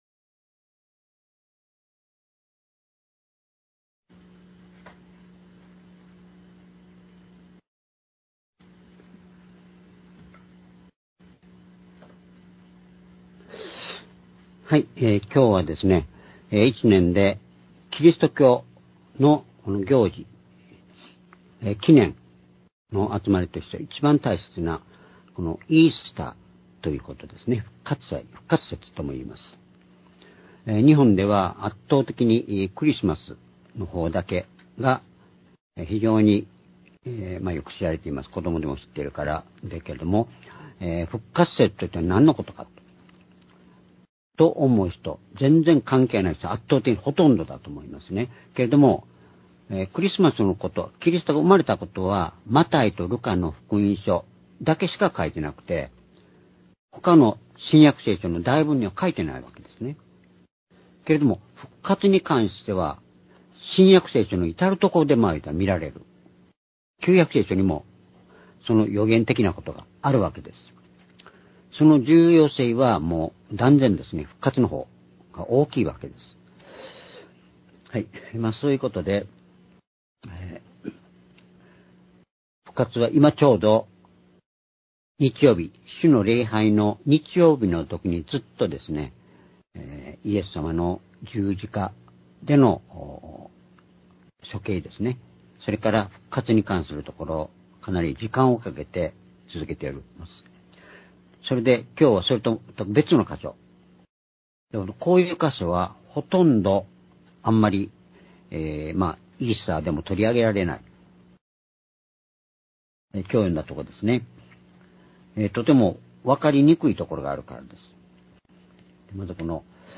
主日礼拝日時 ２２５年４月20日（イースター） 聖書講話箇所 「死せる者から、天の王座へ」 エペソ書２の１-８ ※視聴できない場合は をクリックしてください。